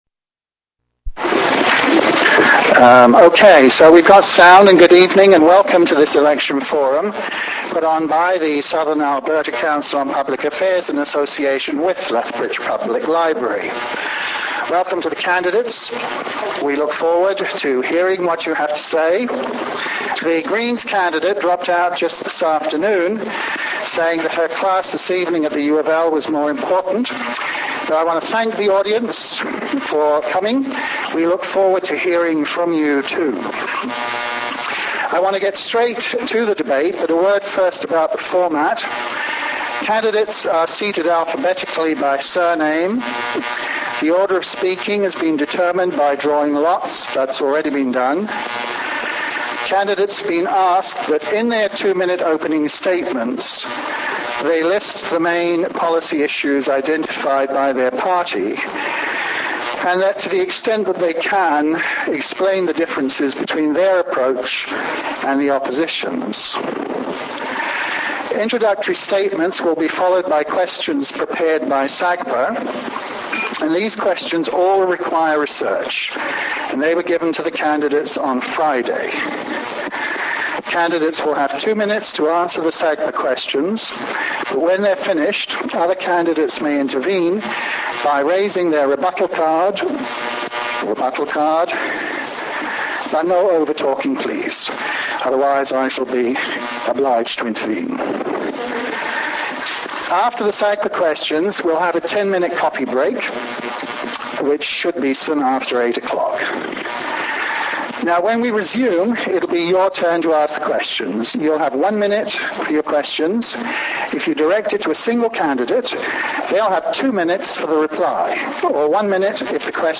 Candidates running for election as the Member of Parliament for Lethbridge will present the position of their Party on some of the main issues and answer questions prepared by SACPA and those asked directly from the audience.
Speakers: Candidates
Venue: Lethbridge Public Library (Theatre Gallery)